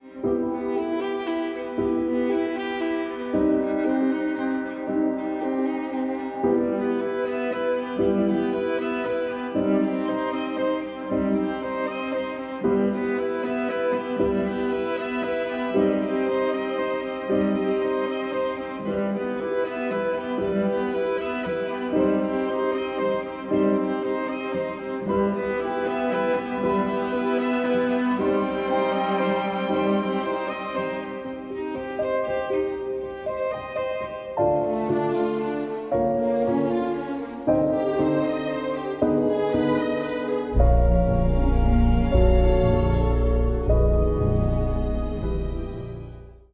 blending electronic sounds and acoustic strings